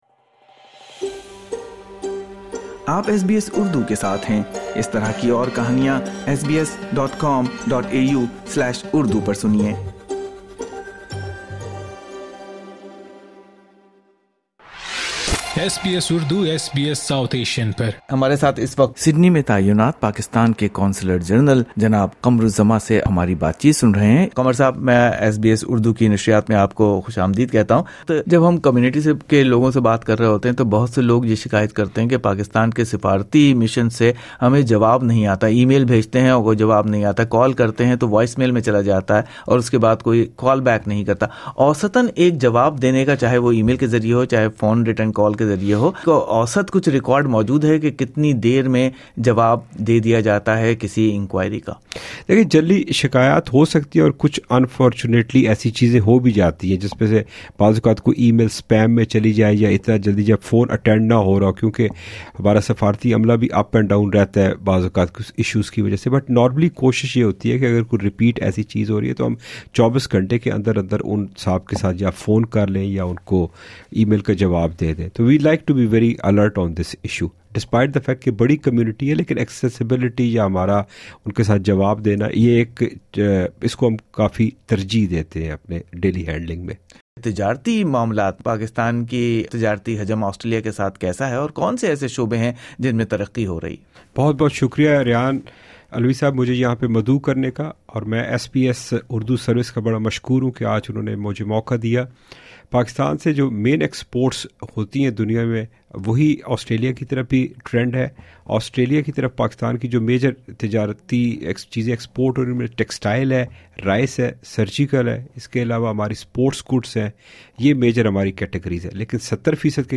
ڈرائیونگ لائسنس کی تصدیق، پاسپورٹ کی تجدید اور ایمرجنسی میں رابطہ، ان تمام موضوعات سمیت کئی اہم کمیونٹی مسائل پر سڈنی میں متعین پاکستانی قونصلر جنرل جناب قمر الزماں کے اس معلوماتی انٹرویو میں سنئے نیو ساؤتھ ویل میں پاکستانی کمیونٹی کو درپیش اہم مسائل پر بات چیت کے ساتھ شکایات کا جوابات اور ایمرجنسی میں مدد کے لیے قونصلیٹ کی خدمات میں بہتری کے اقدامات کی معلومات۔